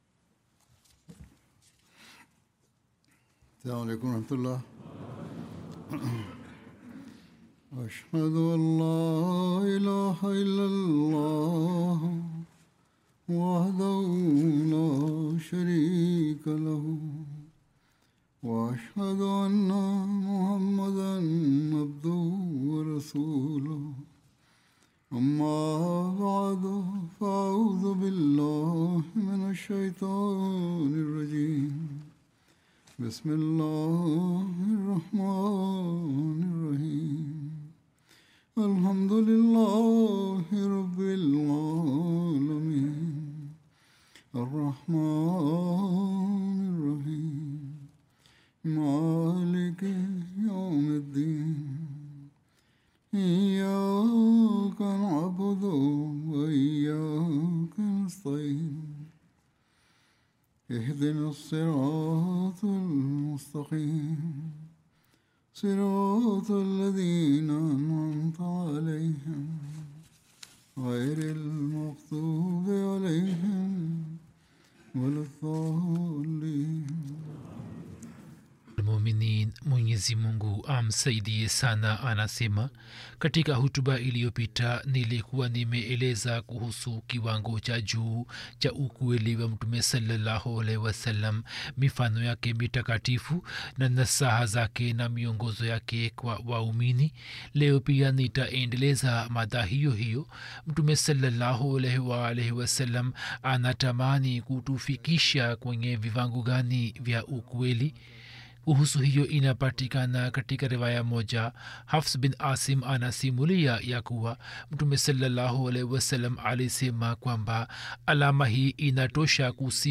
Swahili Friday Sermon by Head of Ahmadiyya Muslim Community